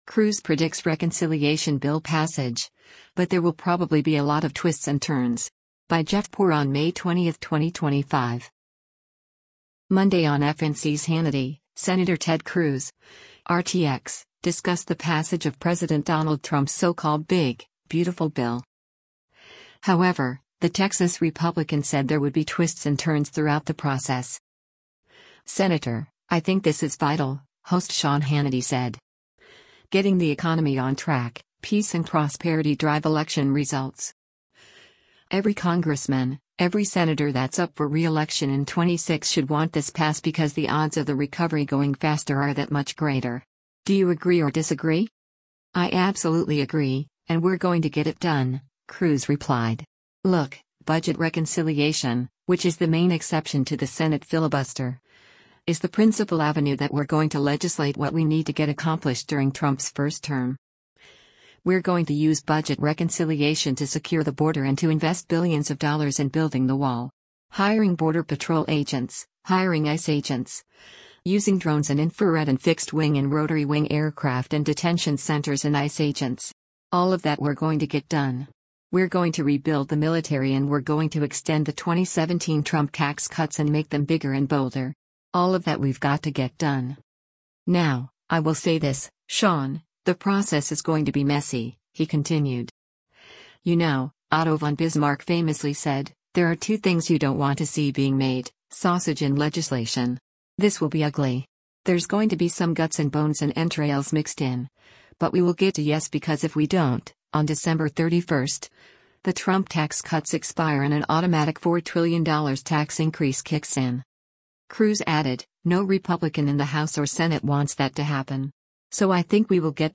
Monday on FNC’s “Hannity,” Sen. Ted Cruz (R-TX) discussed the passage of President Donald Trump’s  so-called “big, beautiful bill.”